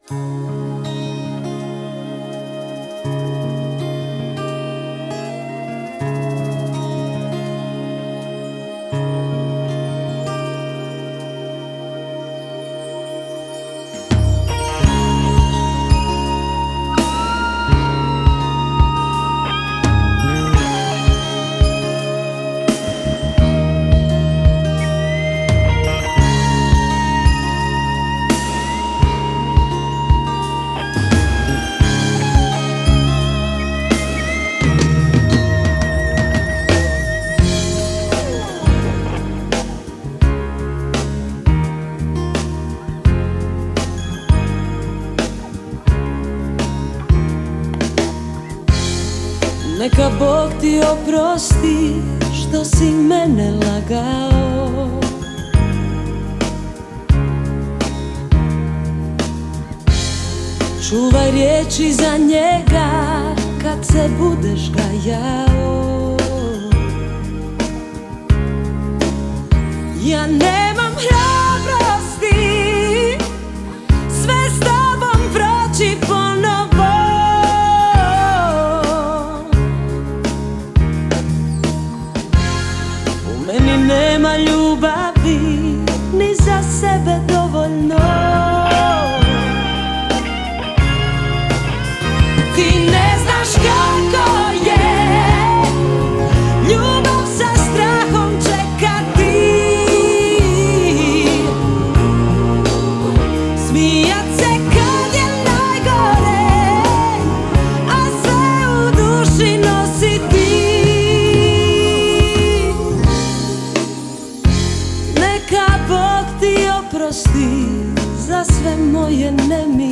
Популярная хорватская поп-певица.